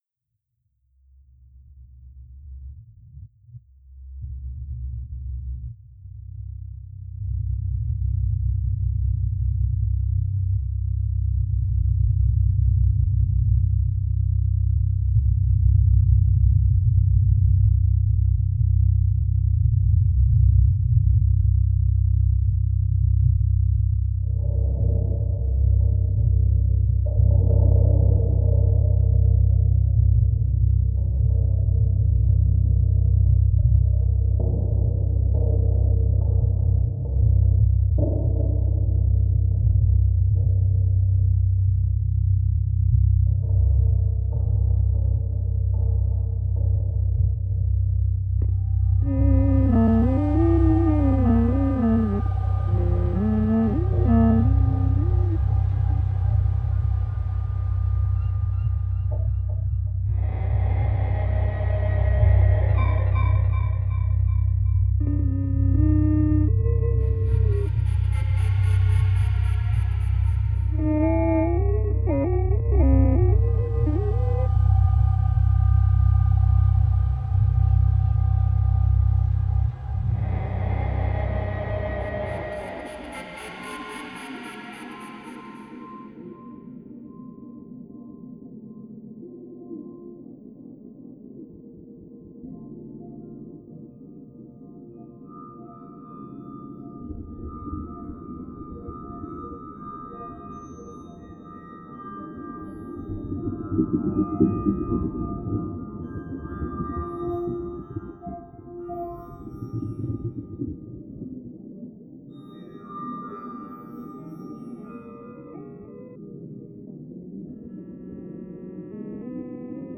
proches de la musique concrète, électroacoustique et électronique
À l’heure du crépuscule est une pièce composée d'instruments de musique (flûte, flûte basse, boîte à tonnerre), de fredonnements, de grincements et de bruits d'eau.
L’atmosphère peut aussi faire penser à des sons sous-marins.